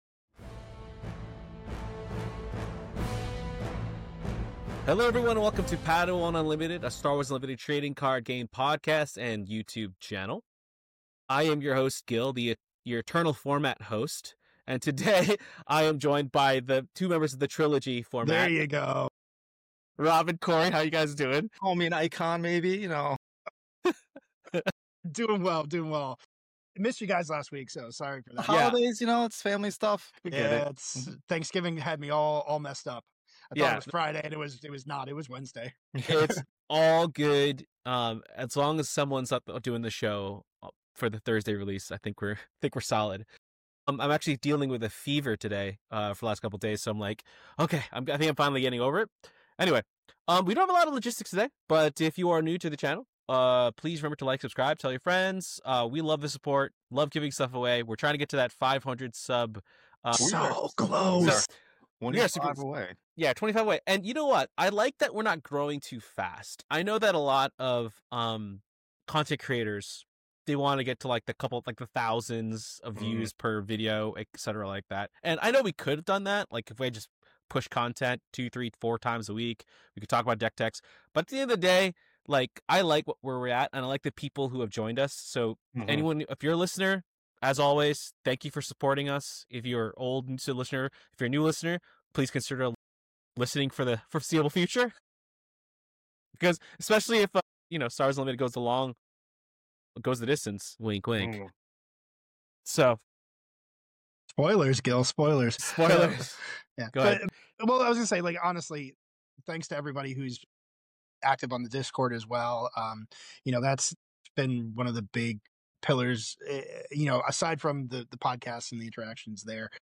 Always upbeat and positive, Padawan Unlimited is a a Star Wars Unlimited (SWU) Trading Card Game podcast dedicated to learning about and building the Star Wars Unlimited community, one episode, one post, one person at a time.